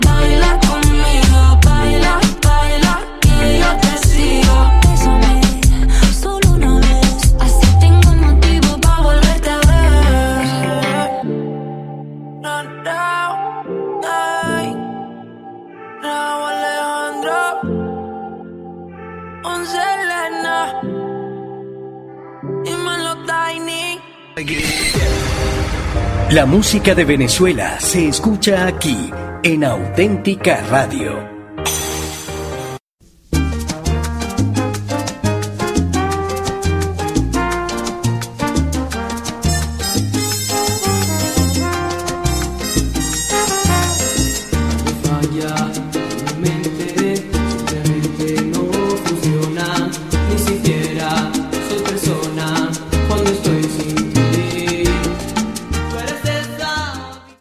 Tema musical, idenfificació i tema musical